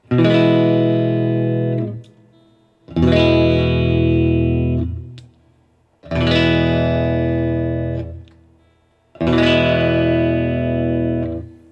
All recordings in this section were recorded with an Olympus LS-10.
For each recording, I cycle through all of the possible pickup combinations, those being (in order): neck pickup, both pickups (in phase), both pickups (out of phase), bridge pickup.
Chords